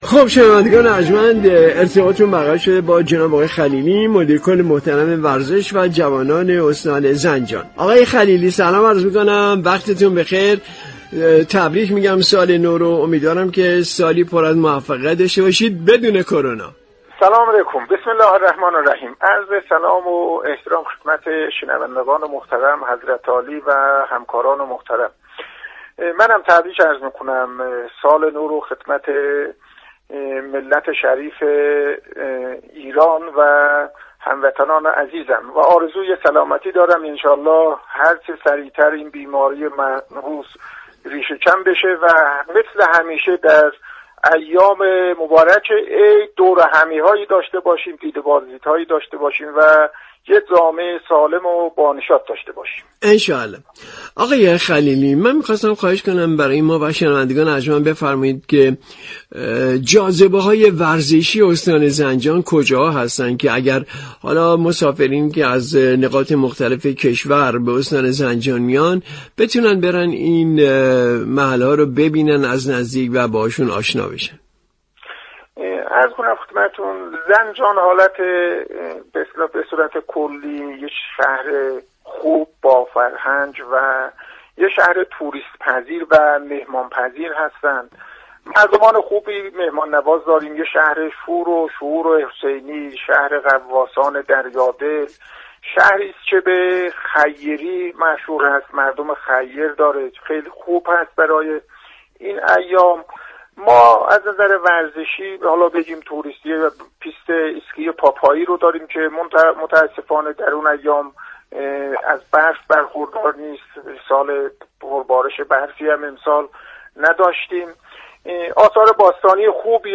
به گزارش رادیو ورزش؛ خلیلی، مدیركل ورزش و جوانان استان زنجان در ارتباط با ویژه برنامه نوروزی ایران ما به گفتگو درباره ظرفیت های ورزشی استان زنجان پرداخت شما می توانید از طریق فایل صوتی پیوست شنونده این گفتگو باشید.